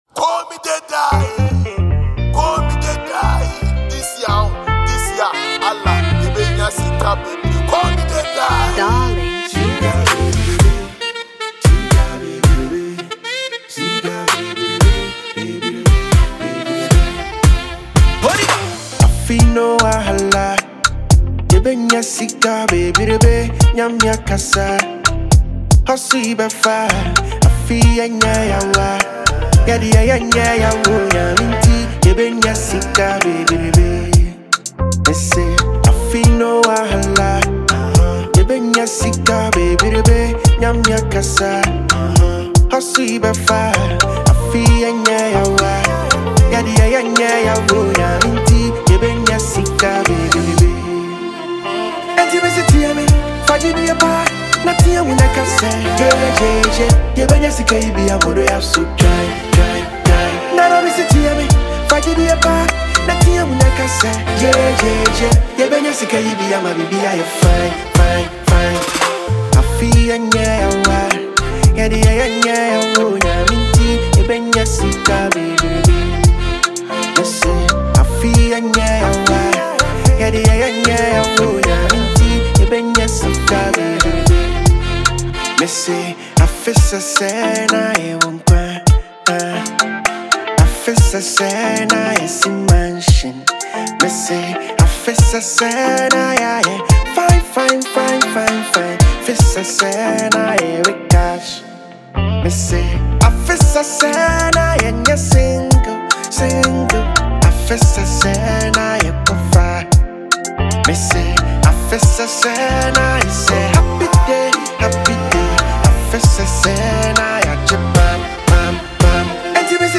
inspiring Ghanaian Afrobeat/highlife record
• Genre: Afrobeat / Highlife